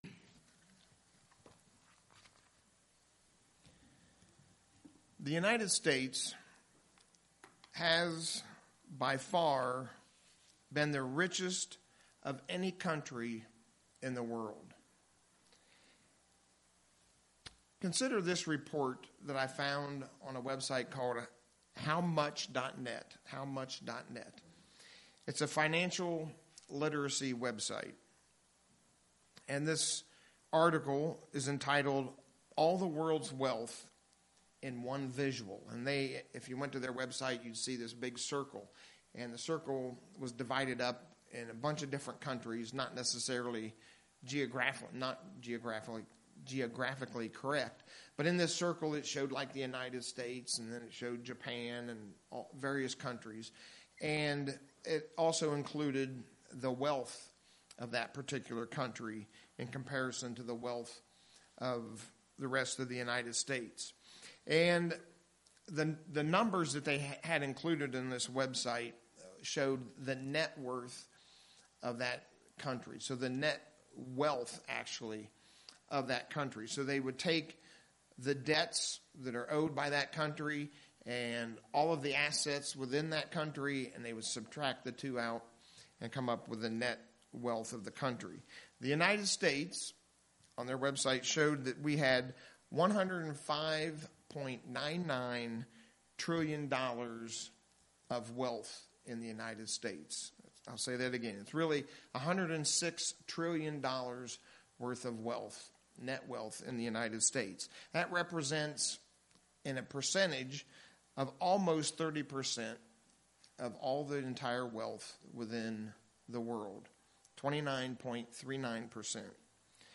In our sermon today, we'll see the true blessedness of living a life poor in spirit!
Given in Lehigh Valley, PA